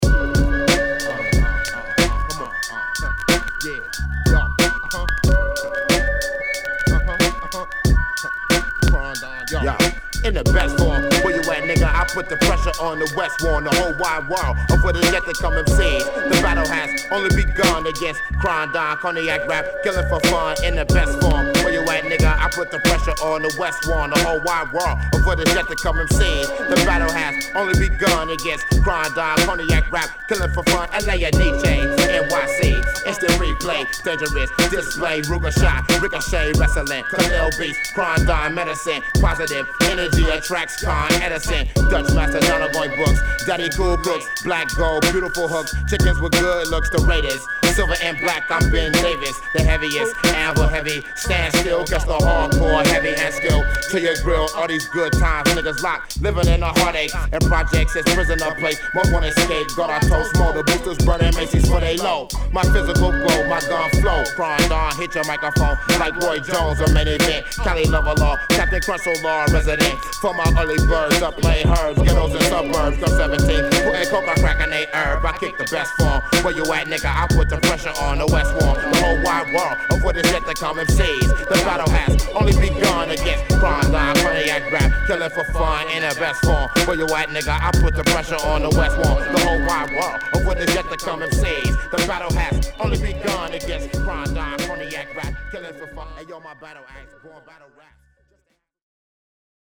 西海岸Underground Hip Hop!!
中毒性のあるループが◎!!